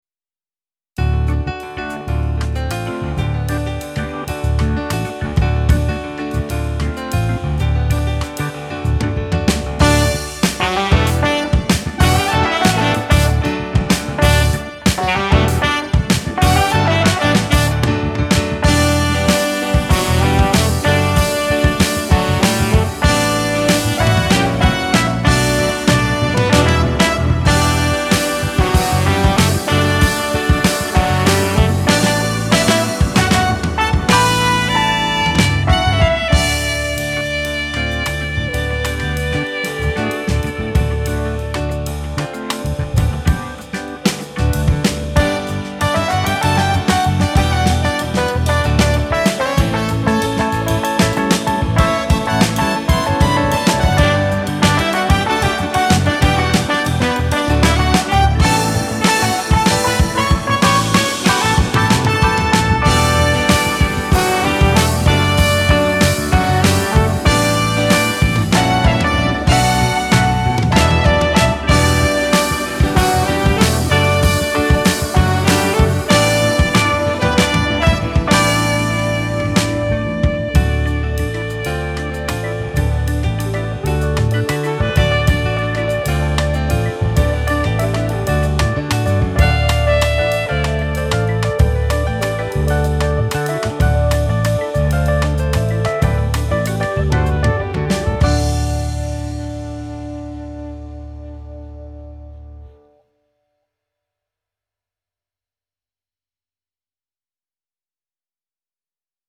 內容類型: R&B & Soul。